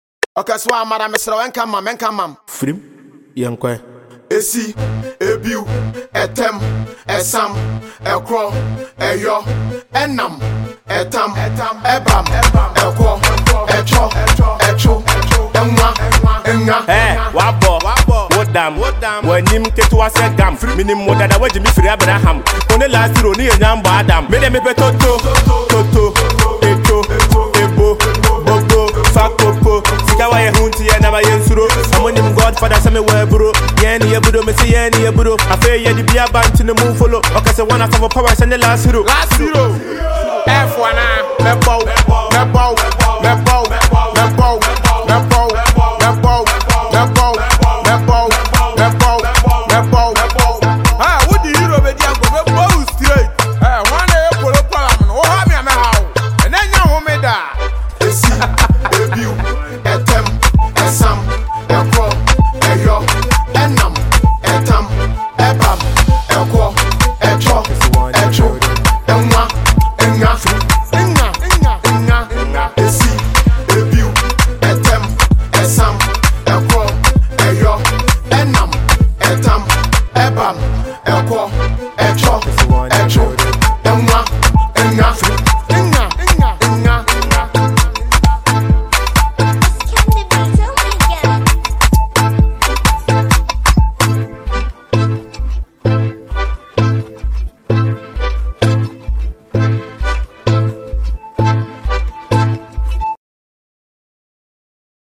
powerful new street anthem